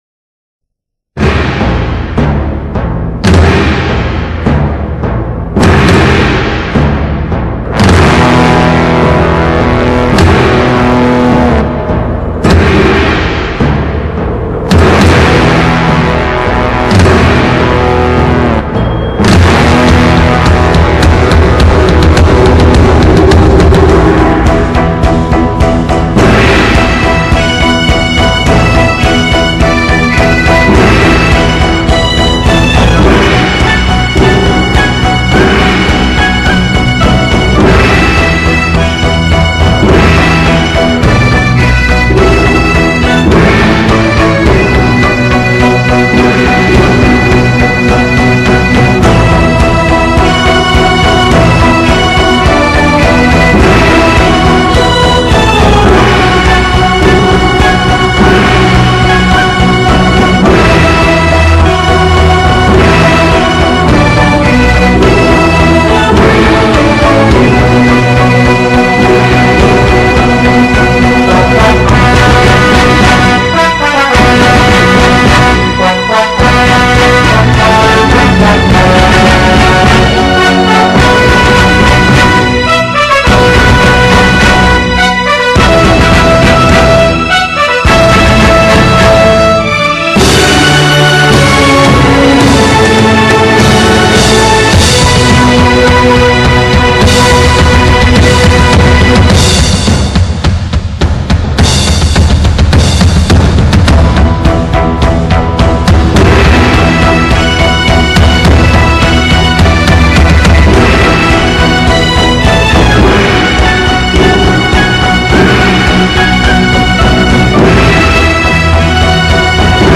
1.以强劲的打击乐为基调，音乐的旋律性有时不是很强，但是却变化多端，每个音符的音长很短。
②专辑类别：原声大碟